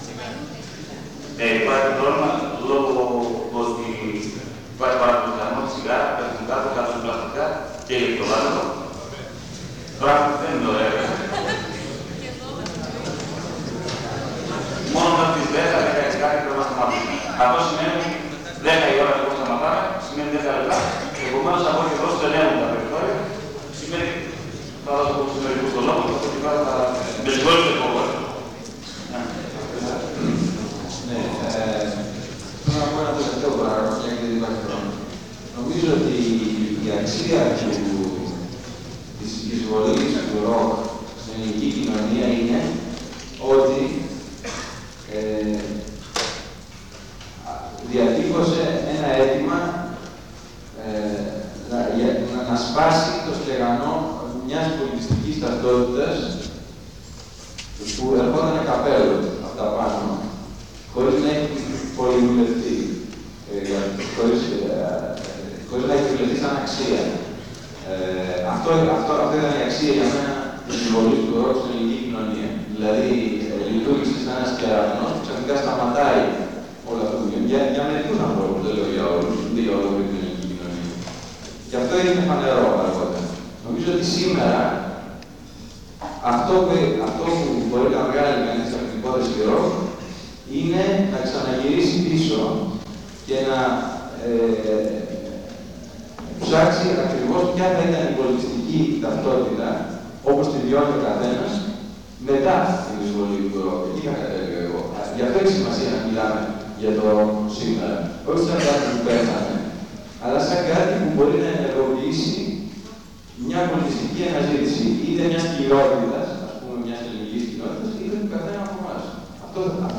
Εξειδίκευση τύπου : Εκδήλωση
Περίληψη: Δημόσια συζήτηση